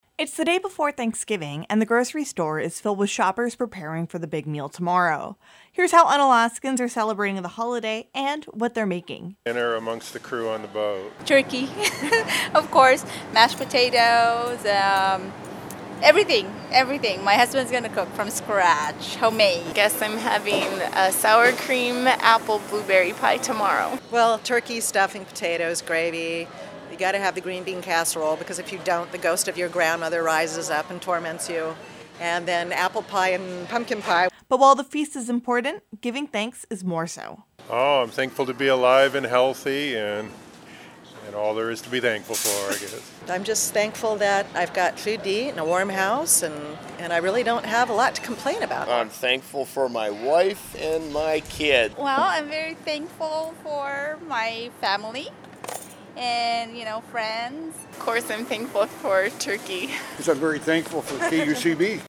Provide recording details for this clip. Unalaska, AK – It's the day before Thanksgiving, and the grocery store is filled with shoppers preparing for the big meal tomorrow.